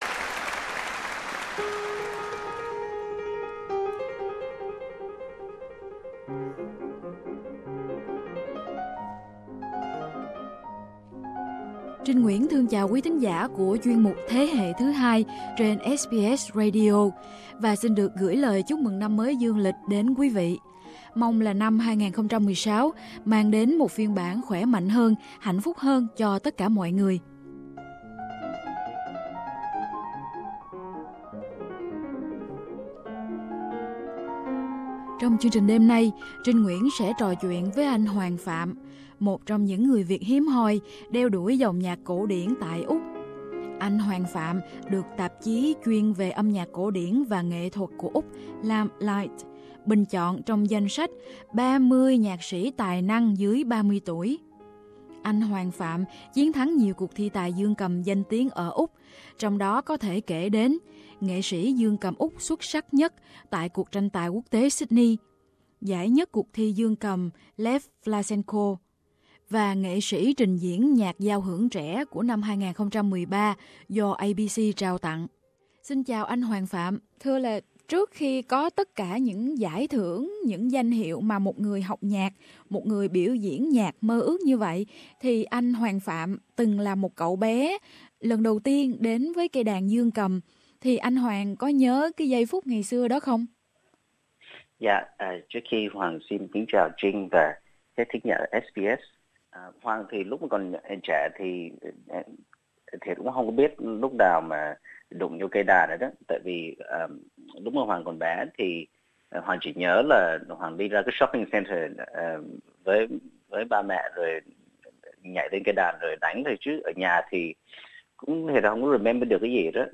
Cuộc trò chuyện đầu năm 2016